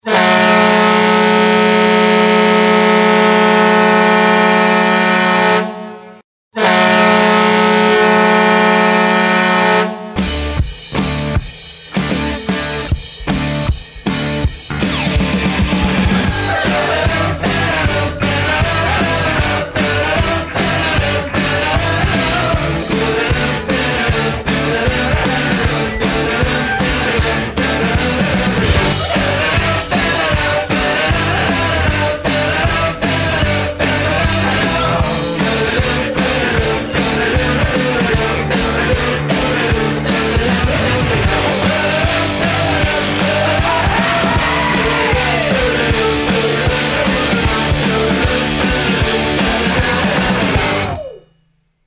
Category: Sports   Right: Personal